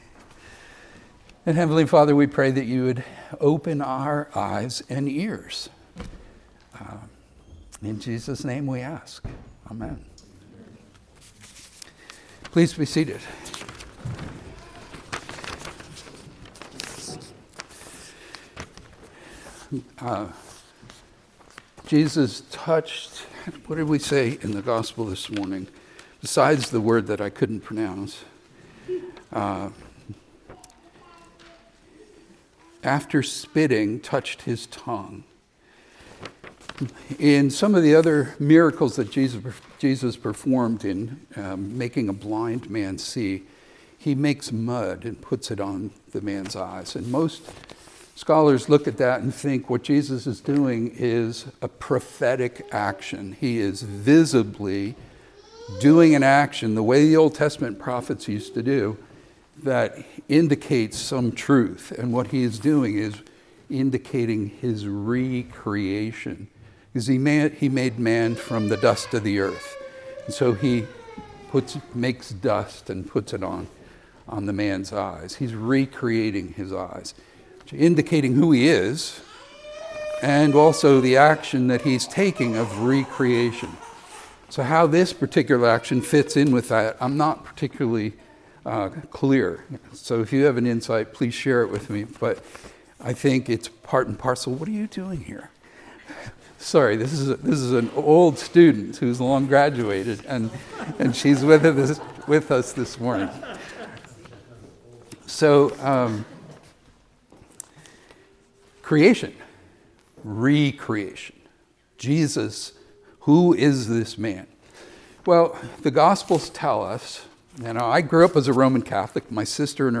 Sermons | Anglican Church of the Ascension